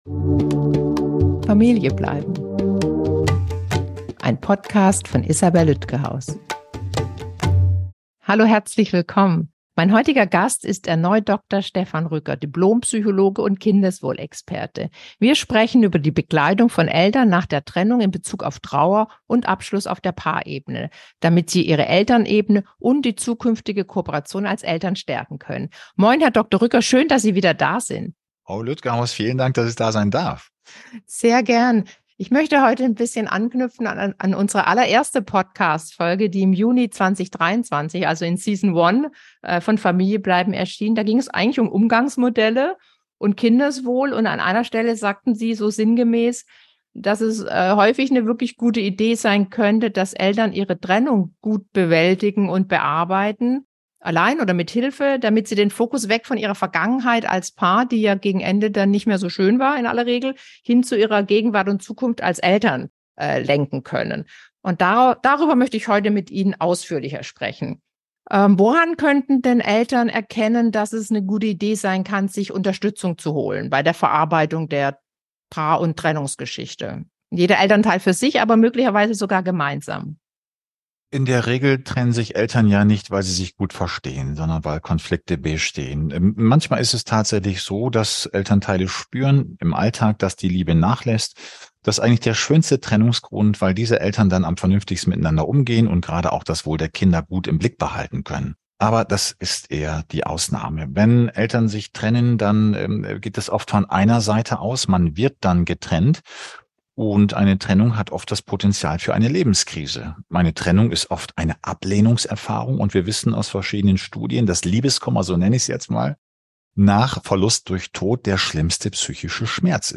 Trauerbegleitung der Eltern bei Trennung: im Gespräch